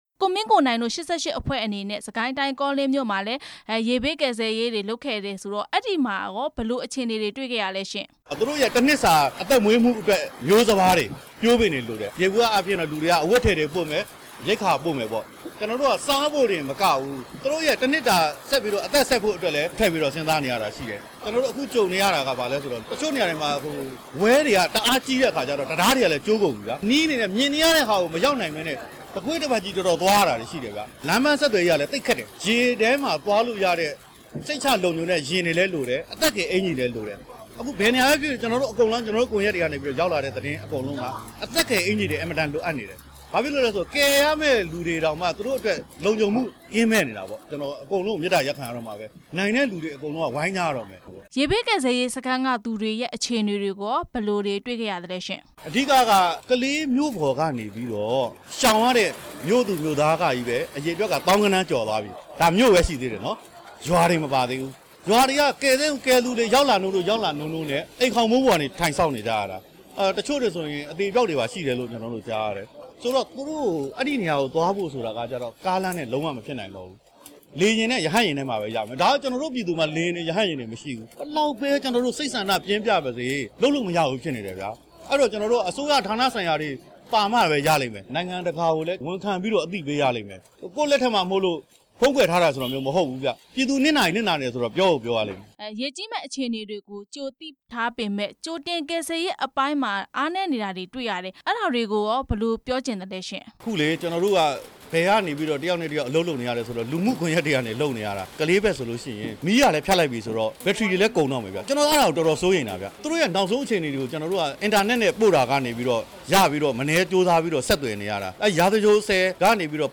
ကိုမင်းကိုနိုင်နဲ့ မေးမြန်းချက်